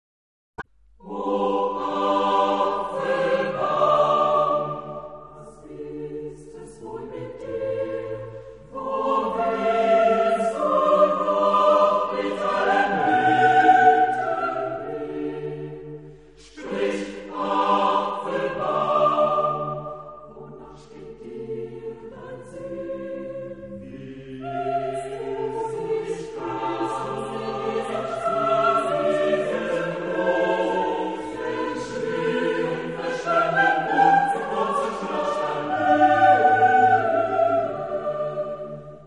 Epoque: 19th century
Genre-Style-Form: Secular ; Romantic
Type of Choir: SATB  (4 mixed voices )
Tonality: E minor